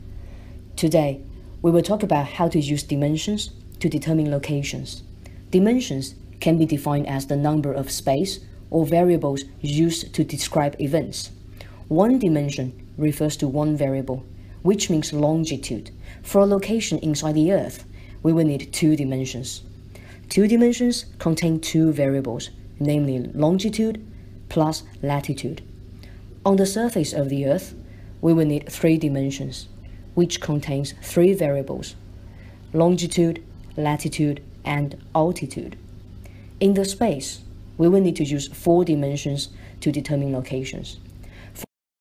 You will hear a lecture.